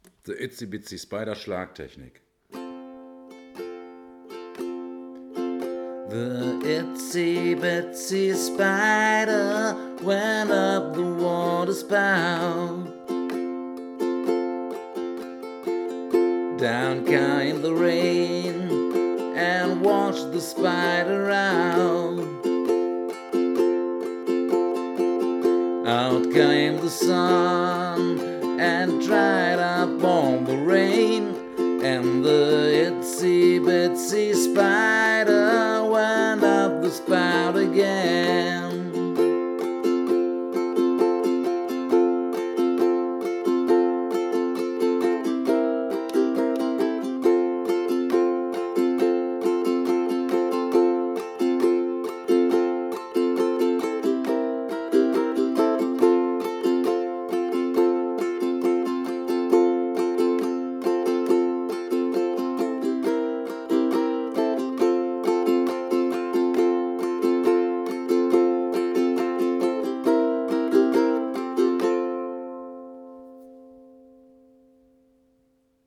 39bItsy-Bitsy-Spider_Schlag.mp3